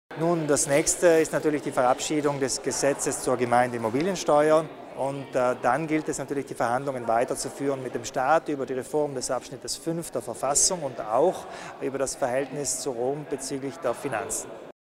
Landeshauptmann Kompatscher erläutert die nächsten Ziele